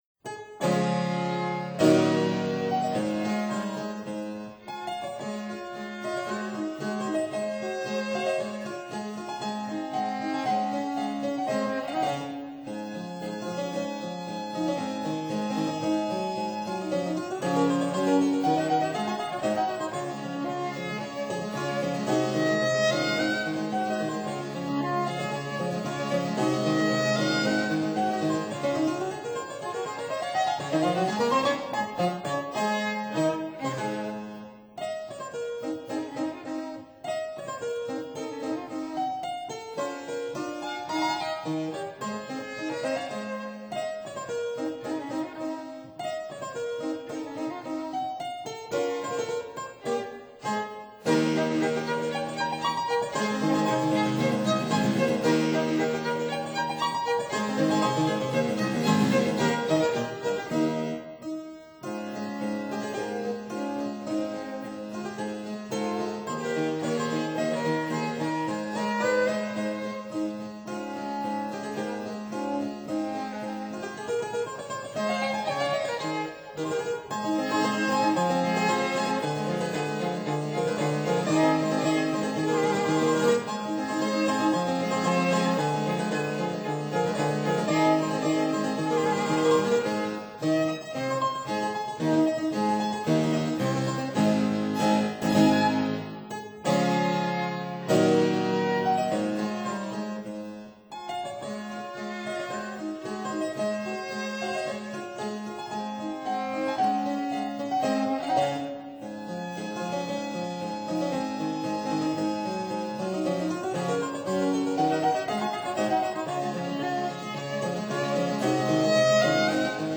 Tangent Piano
Violin
(Period Instruments)